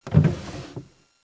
PIANETA GRATIS - Audio Suonerie - Casa (Domestici) - Pagina 18
audio_suonerie_rumori_casa_03.wav